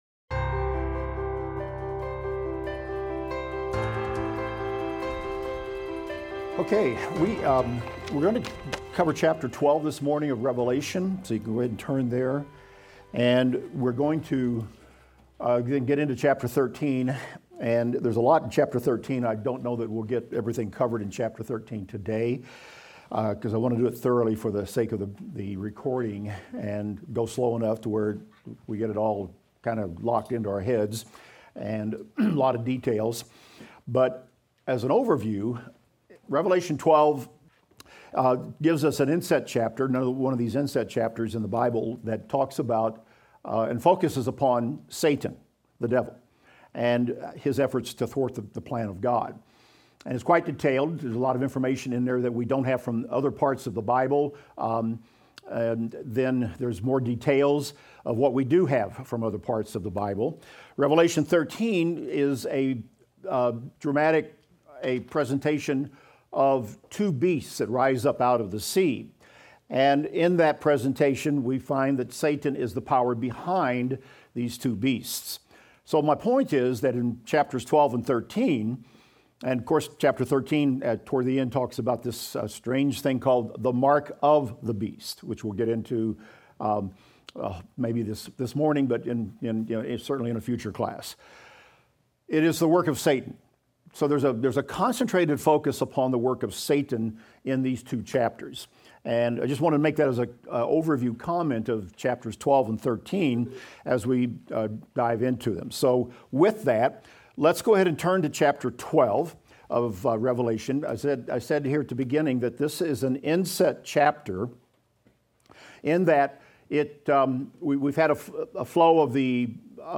Revelation - Lecture 41 - Audio.mp3